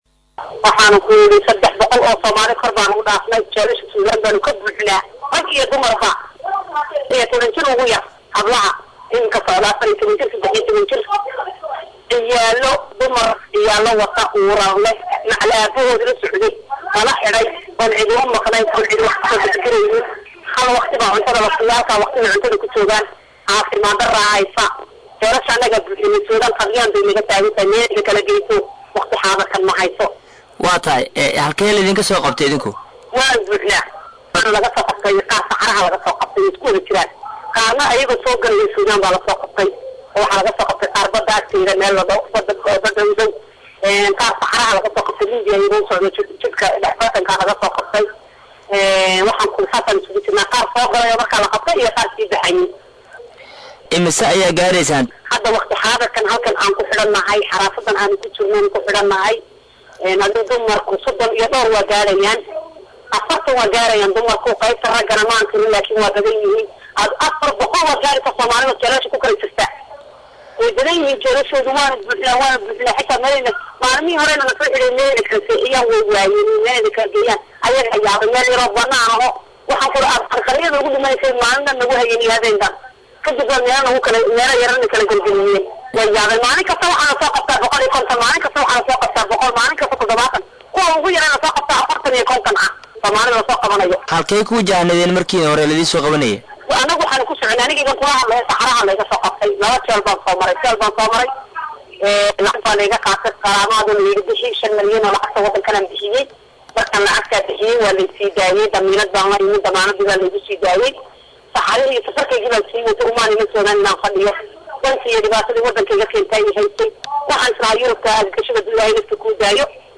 Gabdho ku xiran xabsi ku yaala Sudan kana mid ah dhallinyarada la soo qabtay oo la hadlay Radio Muqdisho ayaa sheegay in ay gaarayaan 40 gabdhood, kuwaasi oo aan heysan daryeel caafimaad iyo wax ay cunaan, isla markaana ay iminka ku jiraan xaalad adag oo xagga noloshada ah, iyagoo xusay in haddii aysan helin gurmad dhinac waliba leh oo ay ugu horeysa in xabsiyada laga sii daayo ay xaaladooda qatar ku sugantahay.